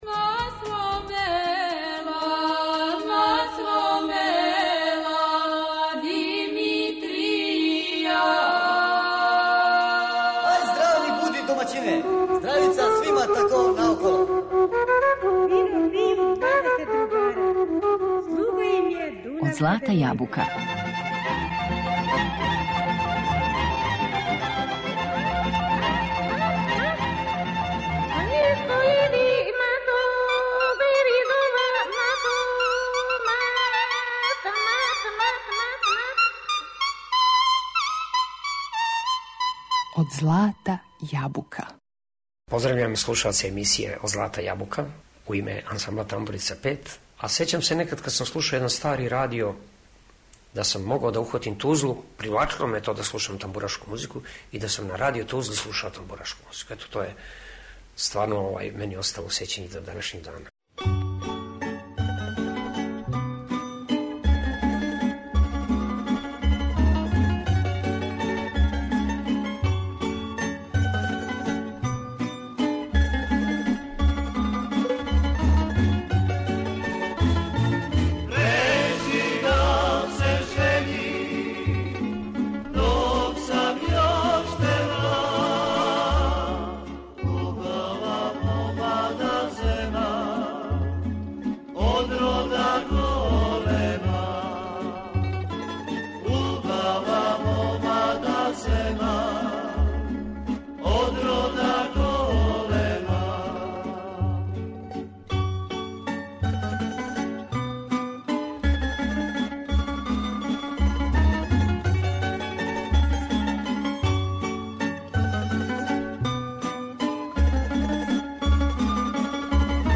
традиционалних народних и градских песама
као и популарне комаде, увертире, чардаше ...